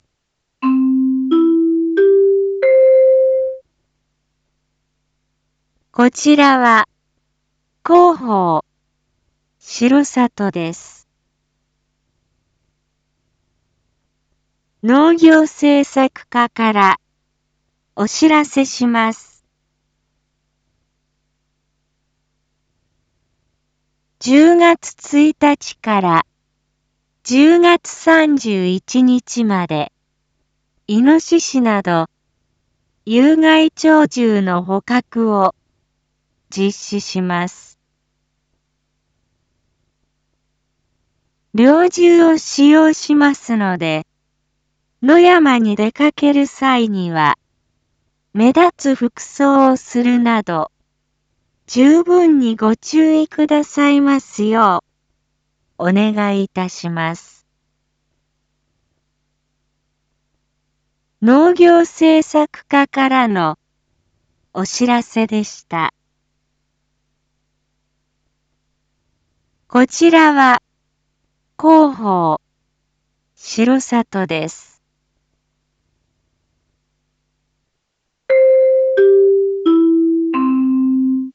Back Home 一般放送情報 音声放送 再生 一般放送情報 登録日時：2024-10-06 07:01:22 タイトル：⑥有害鳥獣捕獲について インフォメーション：こちらは、広報しろさとです。